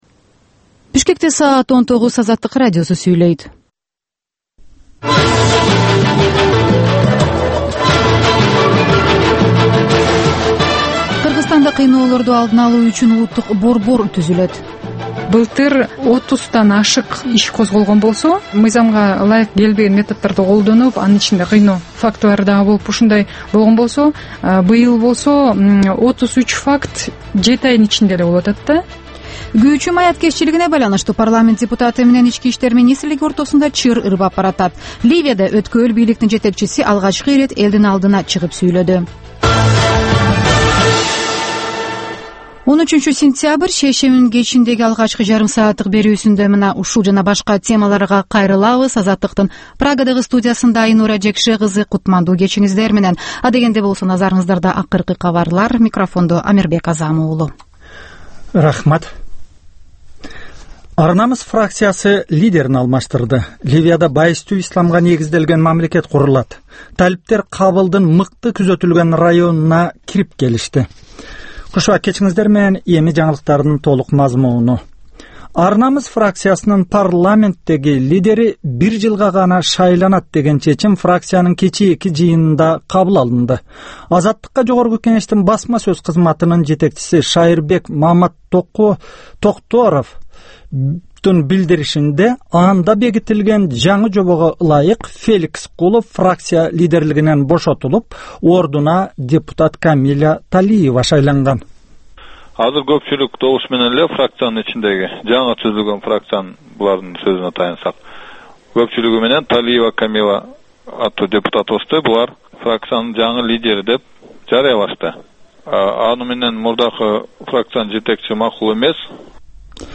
Кечки 7деги кабарлар